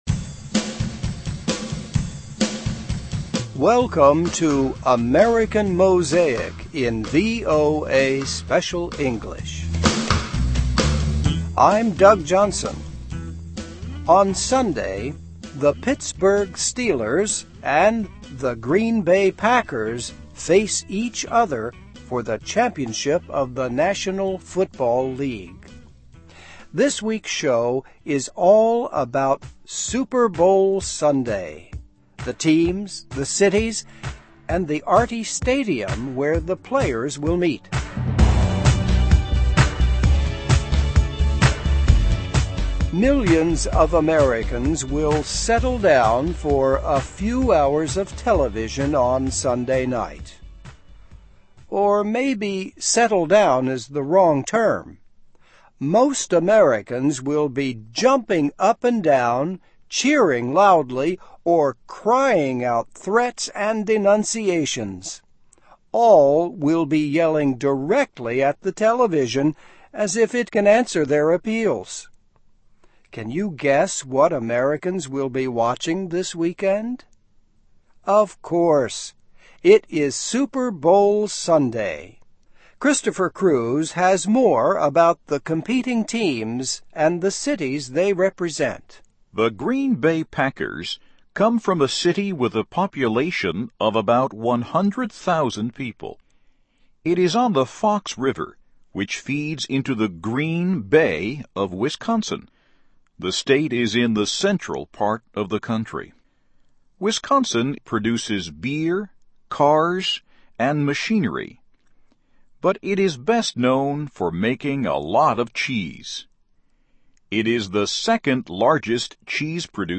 'Cheeseheads' Face 'Terrible Towels' in Super Bowl (VOA Special English 2011-02-03)
Listen and Read Along - Text with Audio - For ESL Students - For Learning English